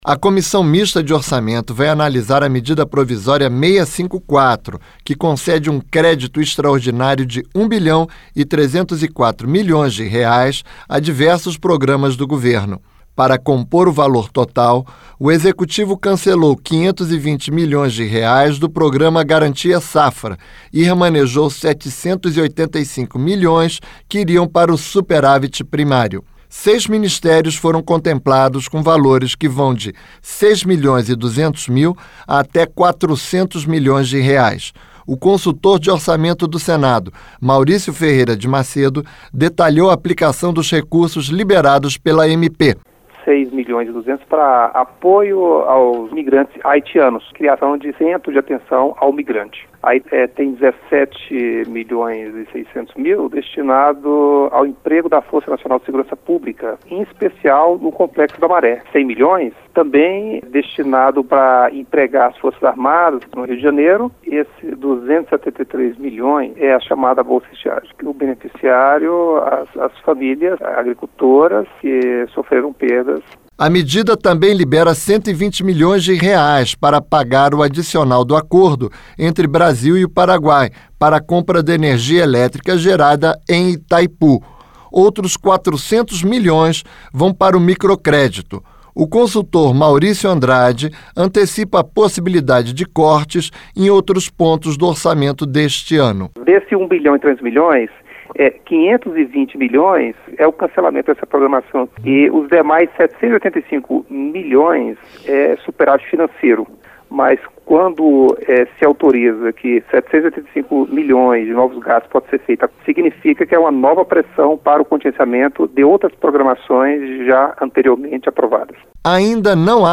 (Repórter) A Comissão Mista de Orçamento vai analisar a Medida Provisória 654, que concede um crédito extraordinário de 1 bilhão e 304 milhões de reais a diversos programas do governo.